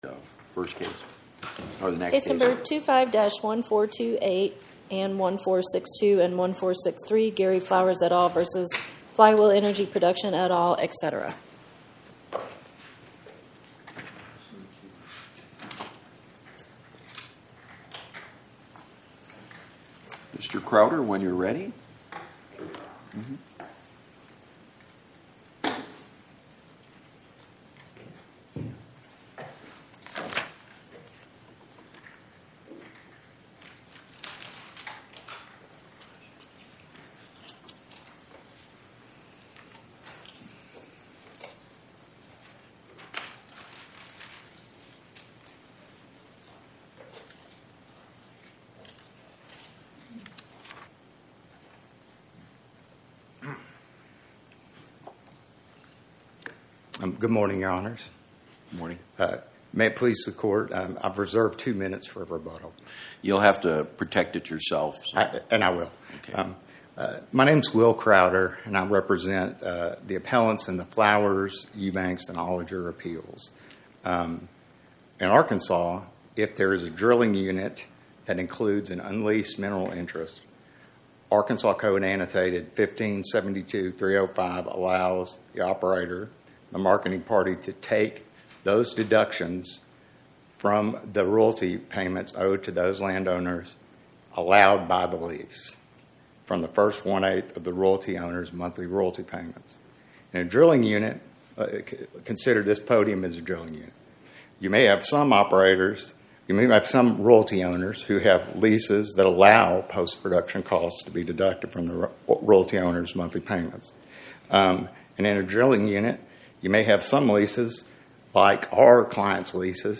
Oral argument argued before the Eighth Circuit U.S. Court of Appeals on or about 01/13/2026